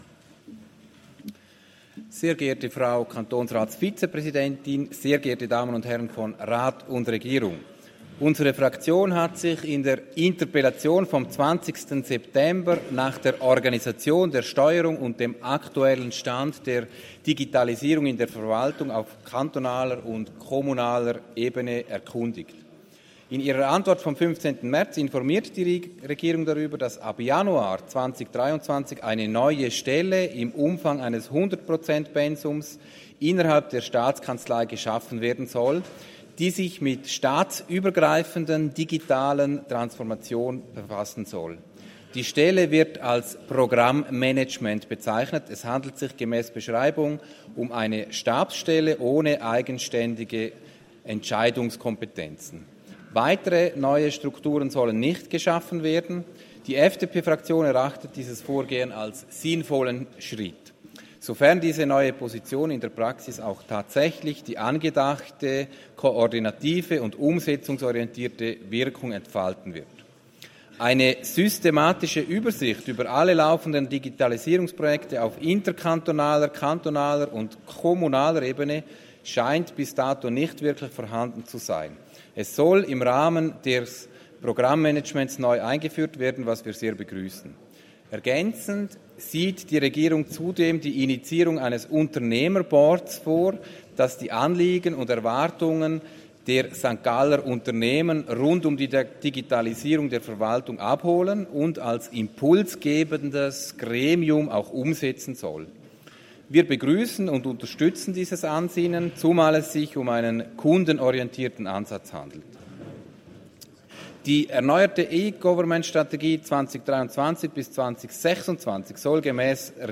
Geschäft 51.21.92 des Kantonsrates St.Gallen